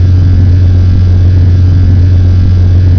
v2500-fullhum.wav